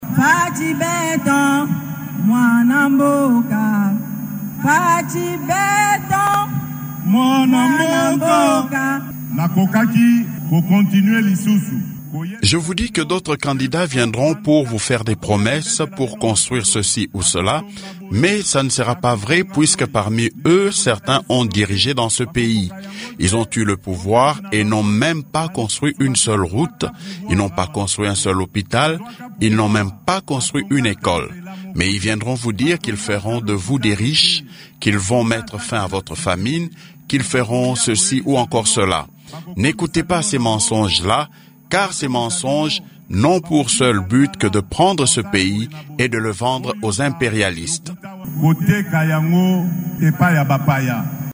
« Rien de mal ne vous arrivera tant que je serai là. Je ne cesserai de vous aimer et même je suis prêt à donner ma vie pour vous », a déclaré Félix-Antoine Tshisekedi ce dimanche19 novembre lors du lancement de sa campagne électorale au Stade des Martyrs de Kinshasa.